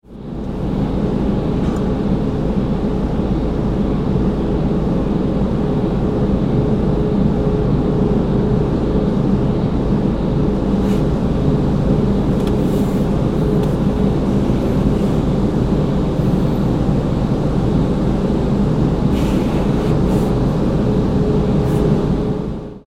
Large-industrial-fan-sound-effect.mp3